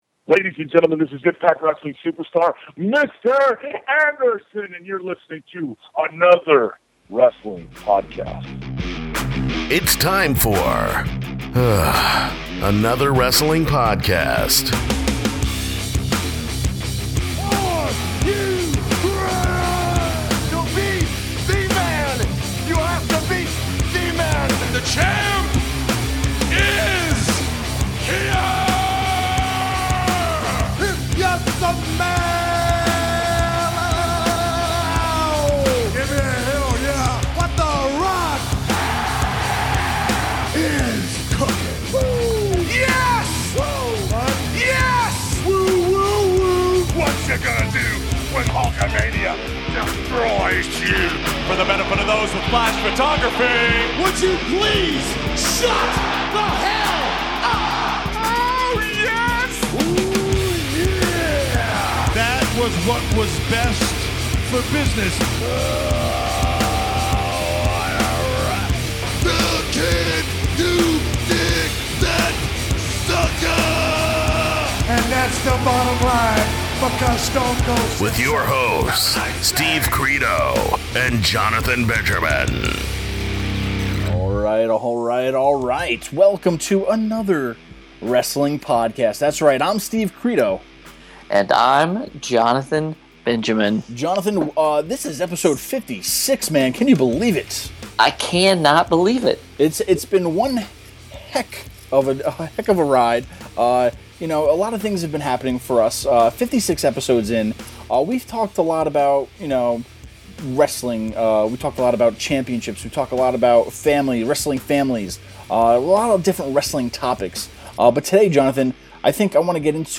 In this episode the guys give a crash course in all things Ring of Honor including the big move to Destination America! Stopping by is the first lady of ROH, Maria Kanellis-Bennett to add a woman's touch to the show.